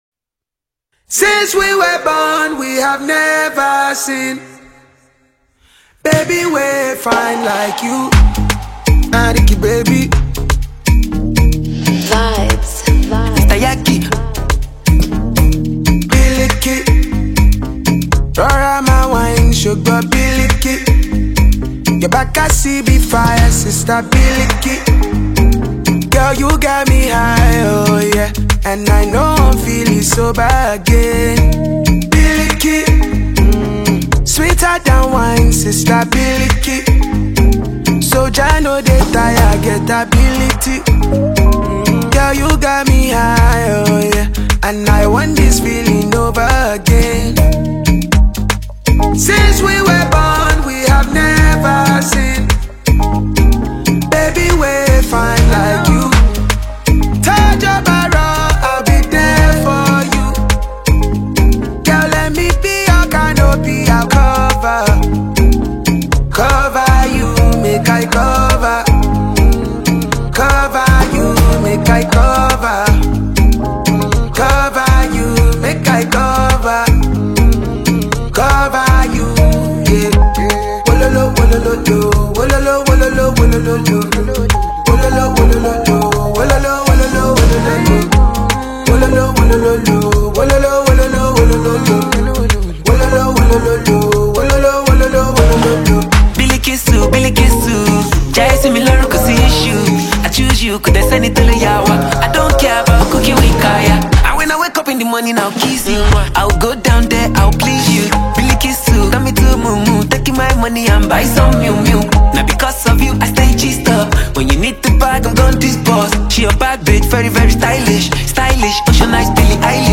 blends Afro-fusion with a street-hop twist